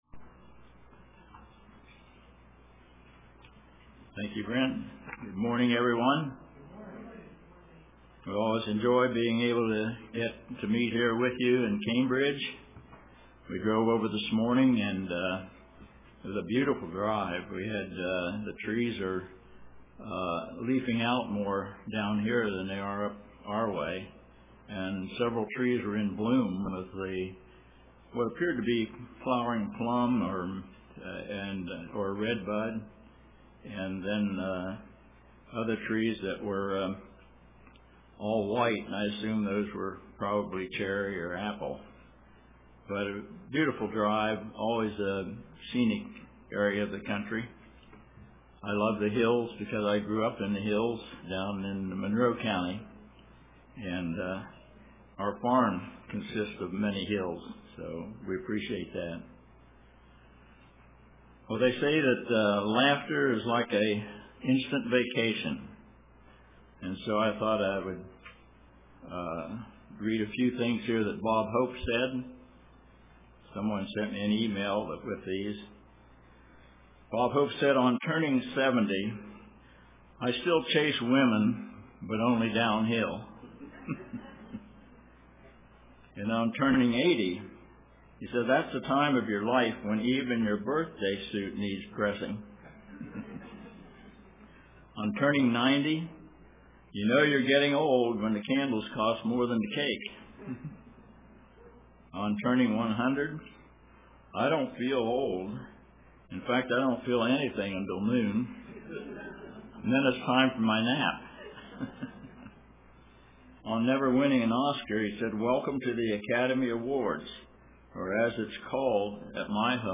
Print Our Infinite God UCG Sermon Studying the bible?